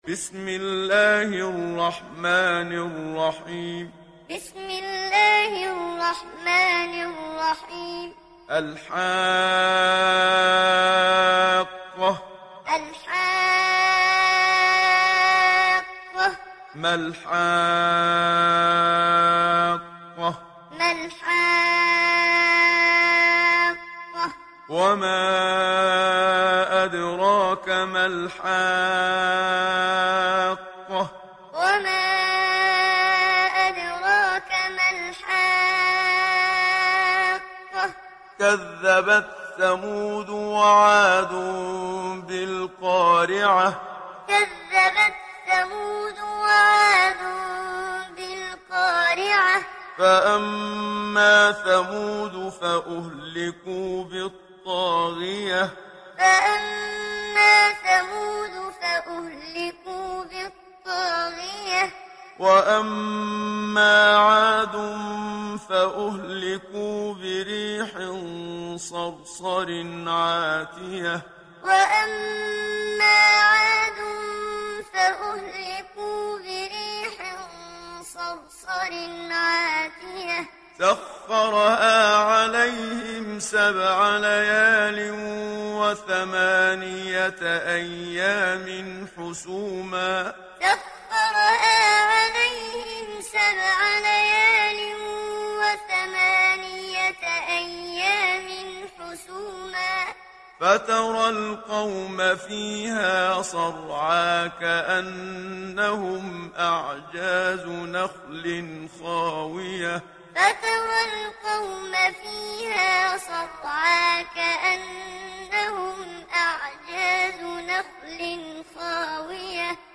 Surat Al Haqqah mp3 Download Muhammad Siddiq Minshawi Muallim (Riwayat Hafs)